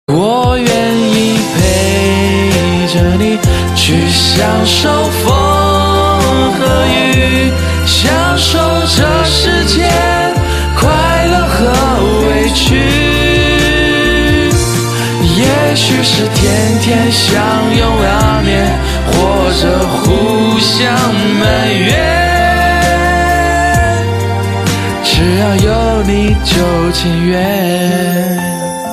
M4R铃声, MP3铃声, 华语歌曲 46 首发日期：2018-05-14 20:51 星期一